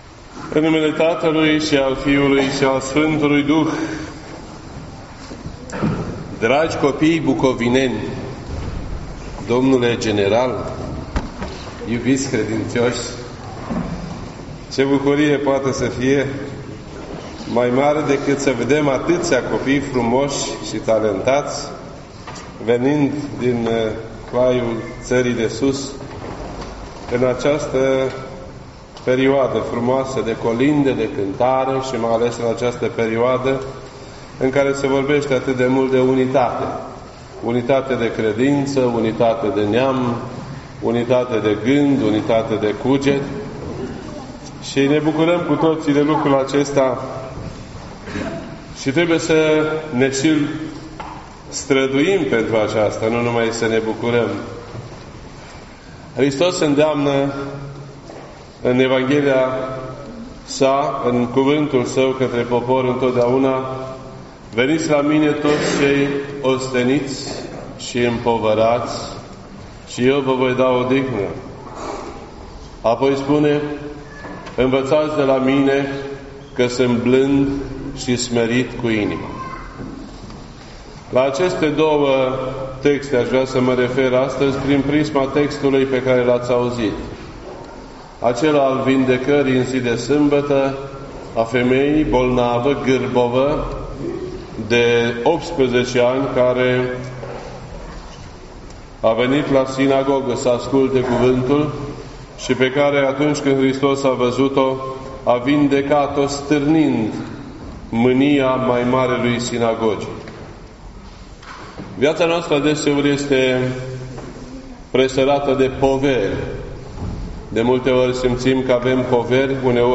This entry was posted on Sunday, December 9th, 2018 at 12:44 PM and is filed under Predici ortodoxe in format audio.